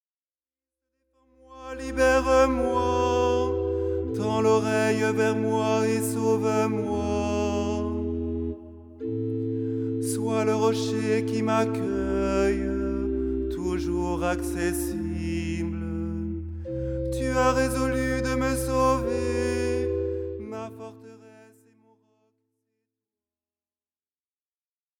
style simple et chantant